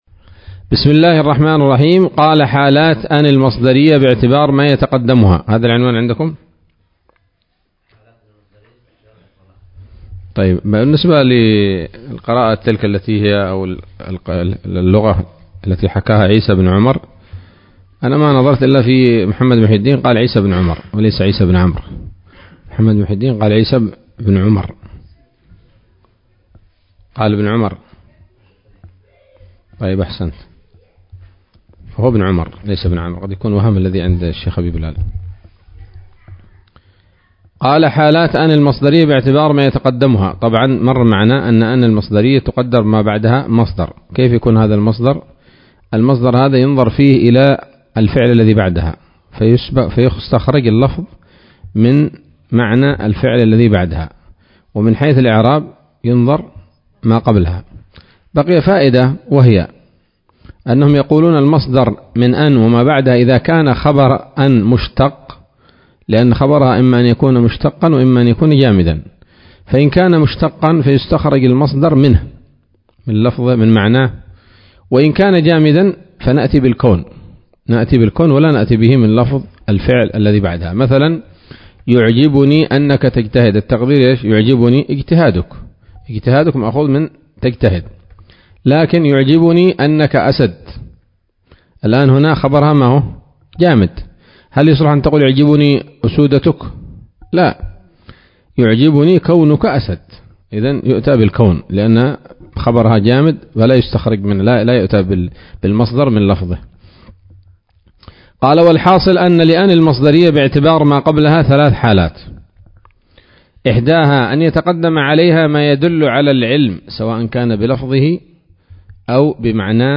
الدرس التاسع والعشرون من شرح قطر الندى وبل الصدى